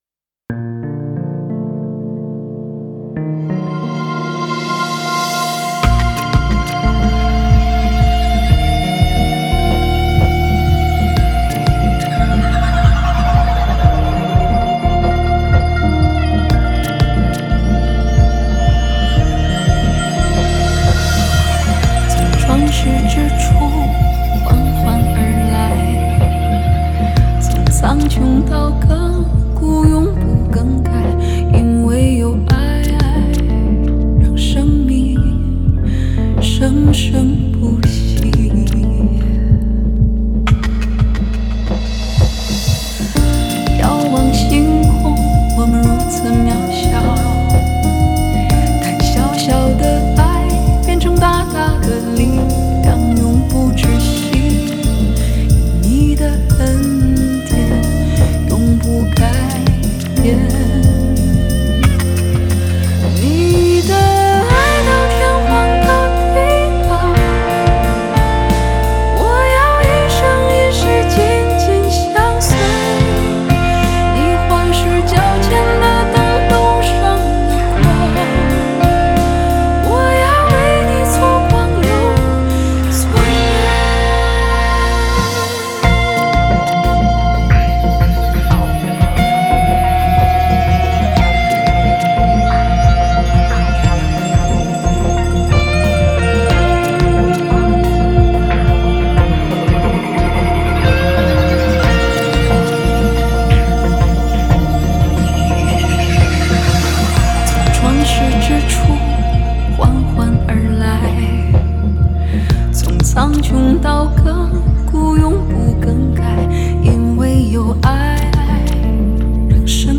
敬拜音乐
HAKA祷告敬拜MP3 启示性祷告： 持续祷告： 祈求神的旨意成就在我们身上，启示性恩膏临到，明白身份，听到呼召，进入命定！